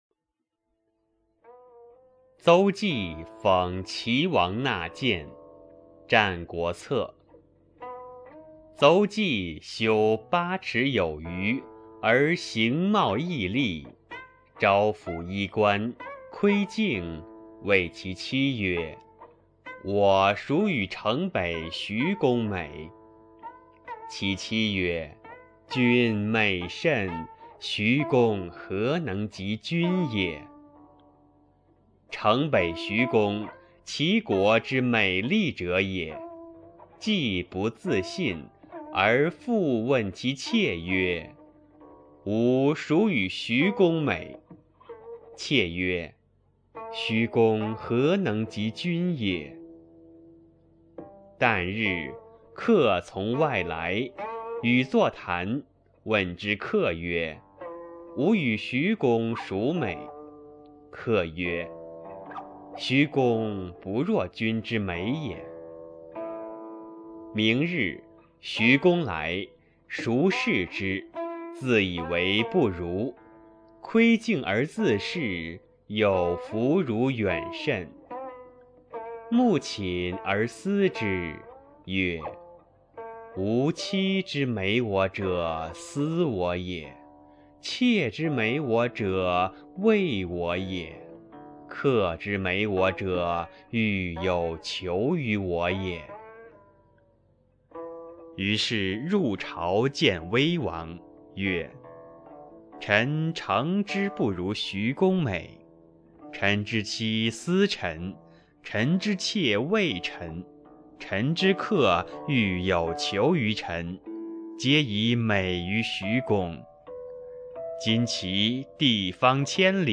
《邹忌讽齐王纳谏》原文与译文（含mp3朗读）　/ 《战国策》